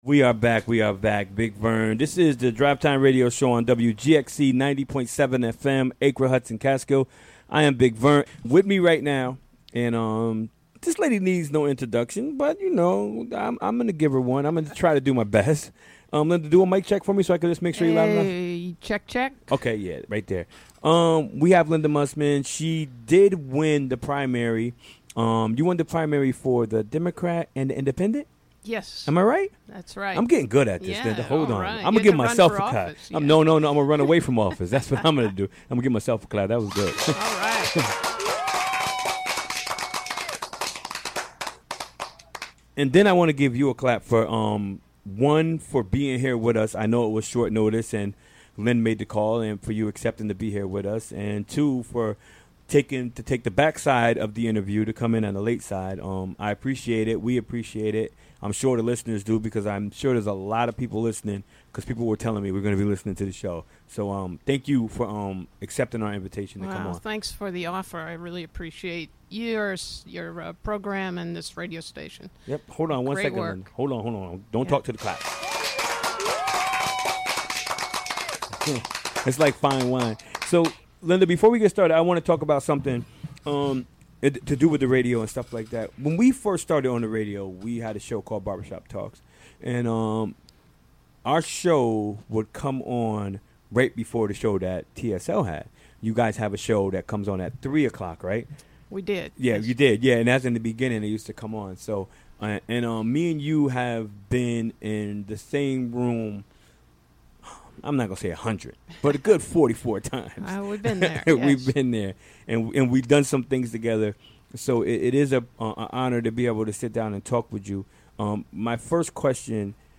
In this broadcast, incumbent Hudson 4th Ward Super...
Recorded during the WGXC Afternoon Show on Wednesday, September 27, 2017.